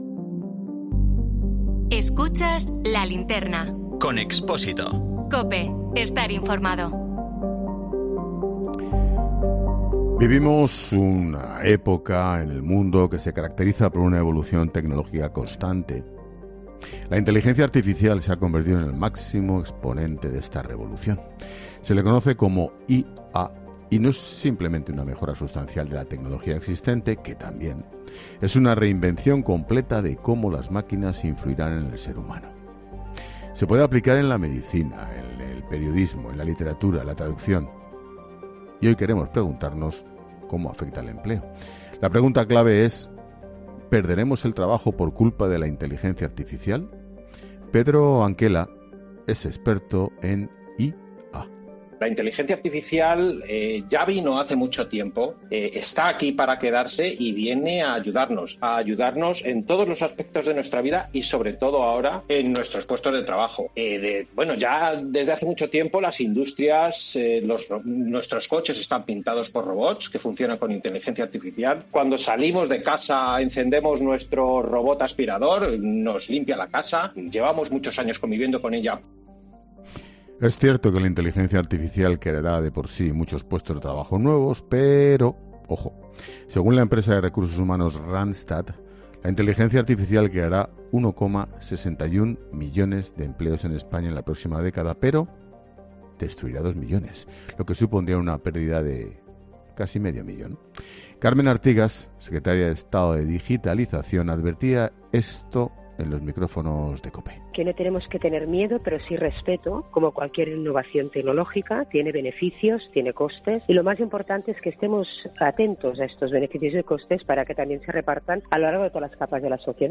Con la Inteligencia Artificial se perderán casi 400.000 empleos en 10 años, en 'La Linterna' varios expertos analizan la futura situación junto a Ángel Expósito